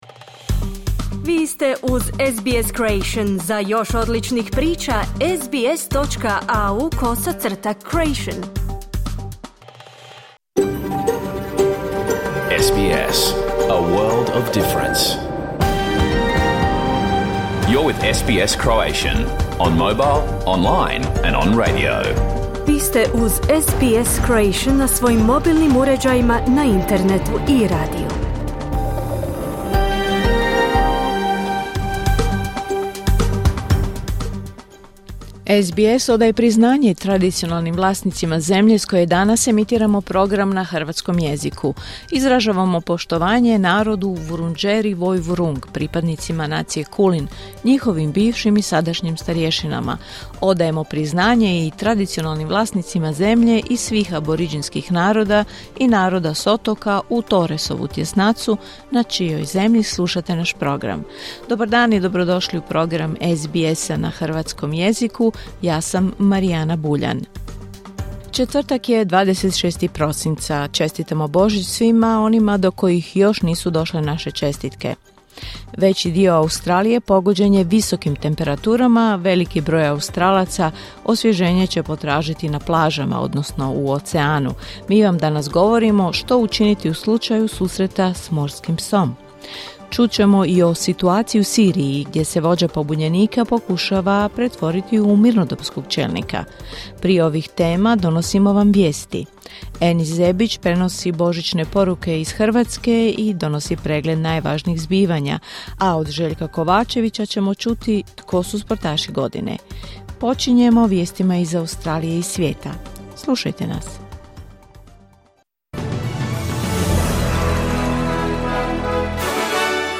Vijesti i aktualnosti iz Australije, Hrvatske i svijeta. Emitirano na radiju SBS1 u 11 sati, po istočnoaustralskom vremenu.